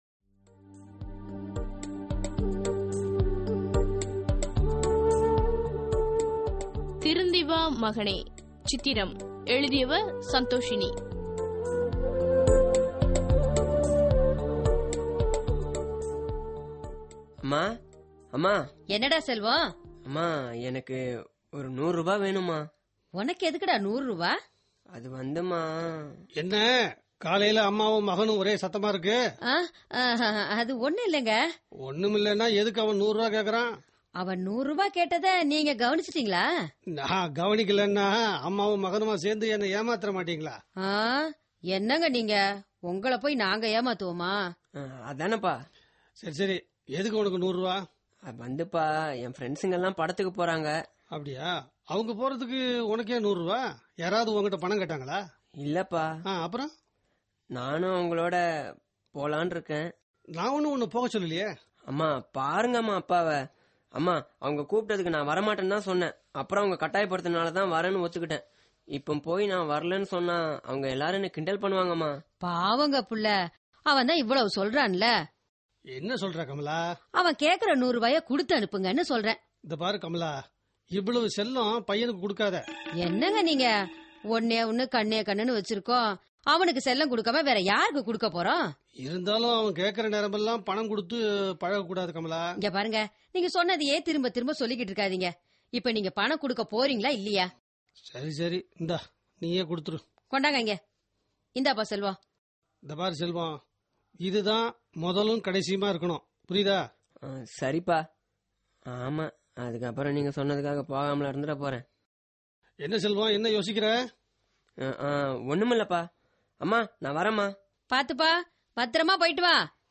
Social Drama